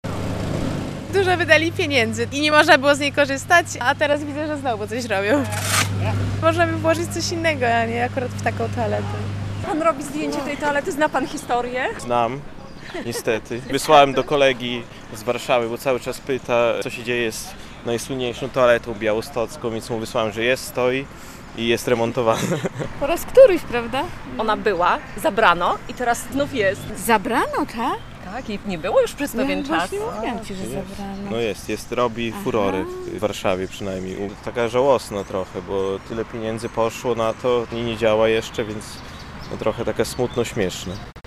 Dalsze losy słynnej, białostockiej toalety - relacja